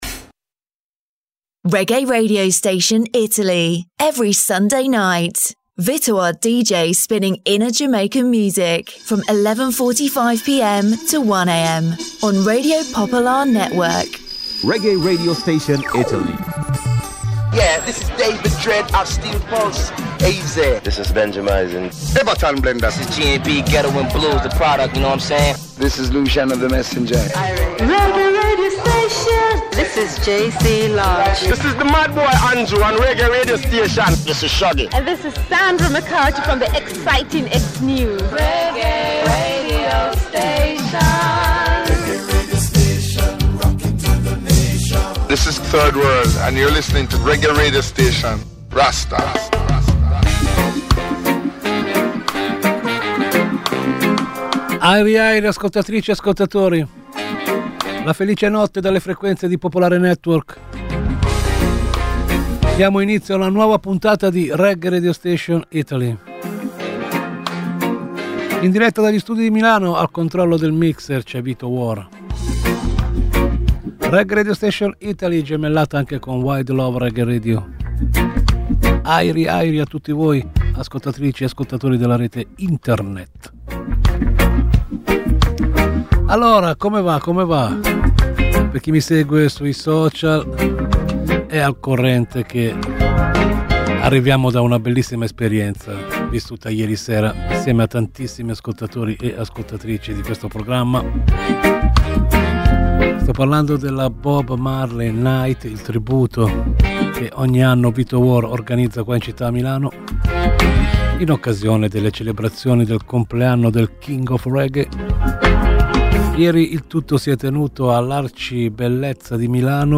A ritmo di Reggae Reggae Radio Station accompagna discretamente l’ascoltatore in un viaggio attraverso le svariate sonorità della Reggae Music e...